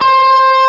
Guitar C Looped Sound Effect
Download a high-quality guitar c looped sound effect.
guitar-c-looped.mp3